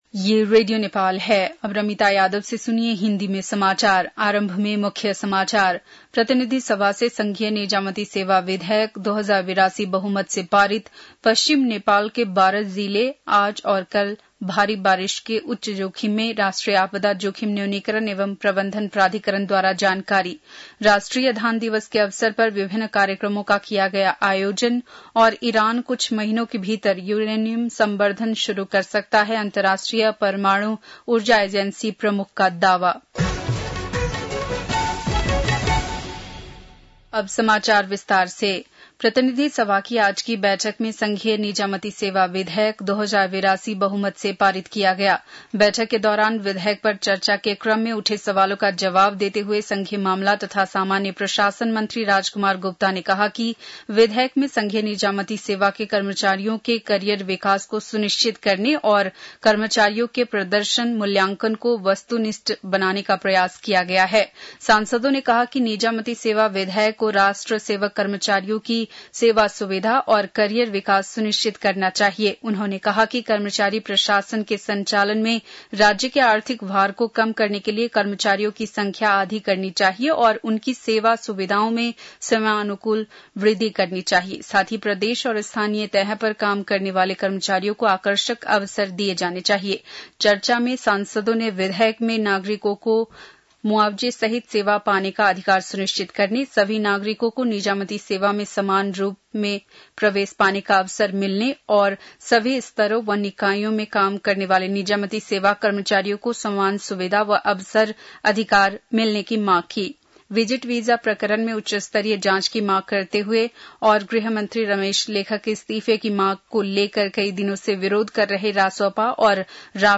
बेलुकी १० बजेको हिन्दी समाचार : १५ असार , २०८२
10-pm-hindi-news-1-2.mp3